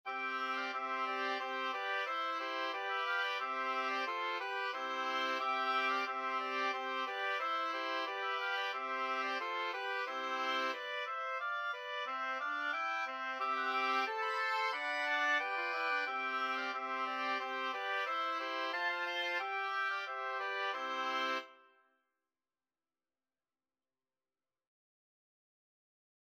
Free Sheet music for Oboe Quartet
Oboe 1Oboe 2Oboe 3Oboe 4
C major (Sounding Pitch) (View more C major Music for Oboe Quartet )
4/4 (View more 4/4 Music)
Oboe Quartet  (View more Easy Oboe Quartet Music)
Traditional (View more Traditional Oboe Quartet Music)